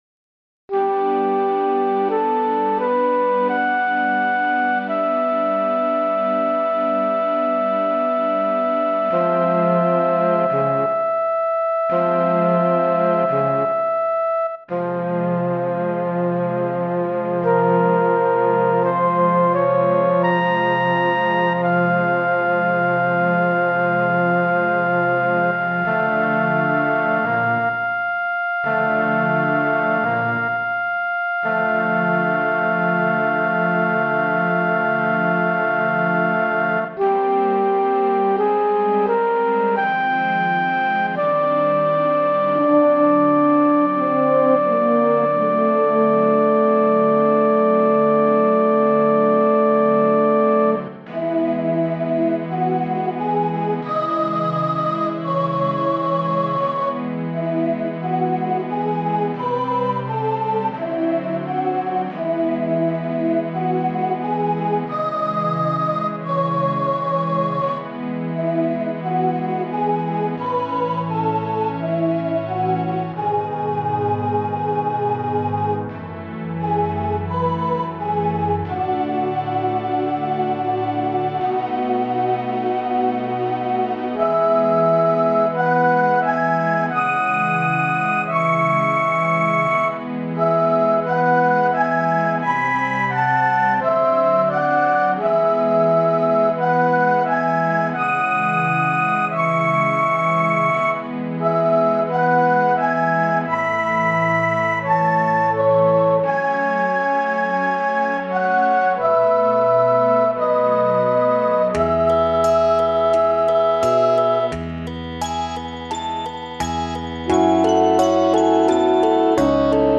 Ver.2(3/9追加：FF5風音色バージョン)mp3ダウンロード